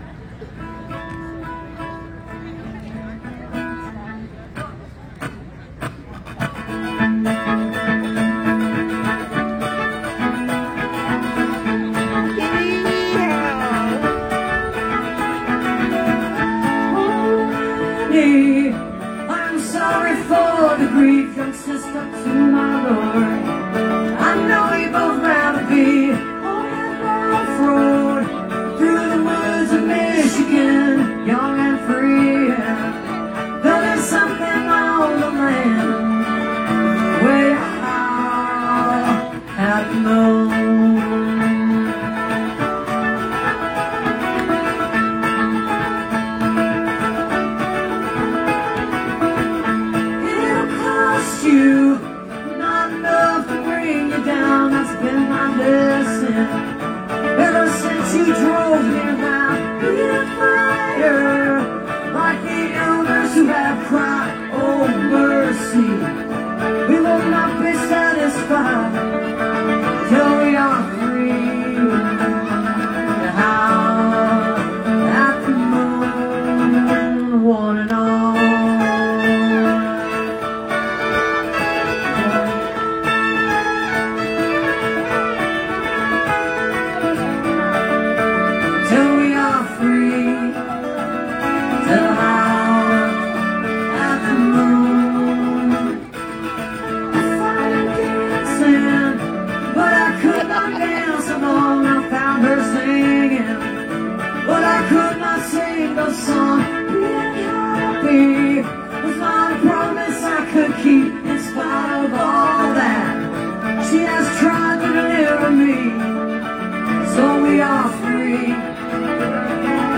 (captured from the facebook livestream of part of the show)